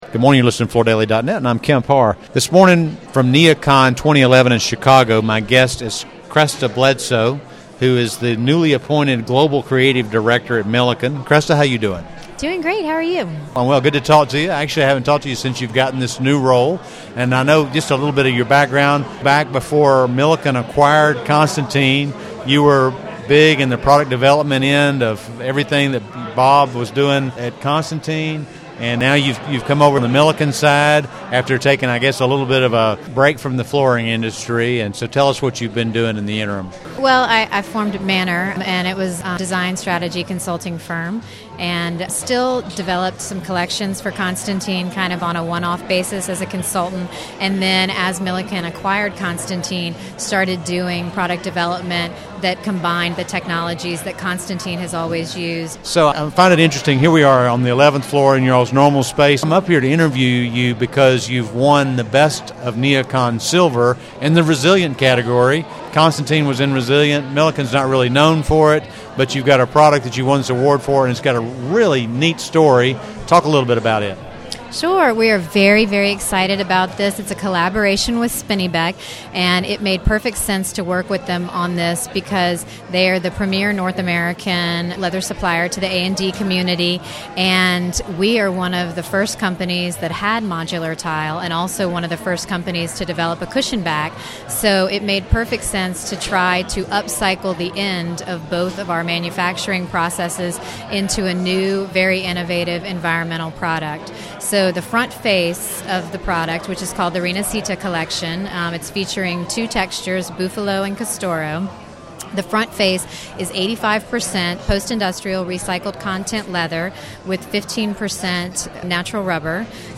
This new product is a collaboration between Milliken and Spinneybeck. Listen to the interview to hear more about this and other key NeoCon news for Milliken.